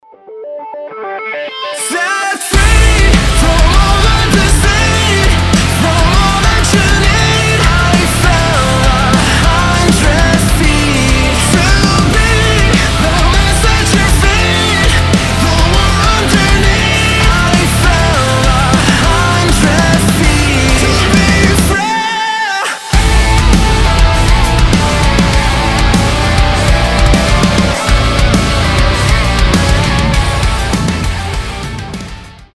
громкие
мощные
Alternative Metal